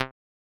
Abstract Click (2).wav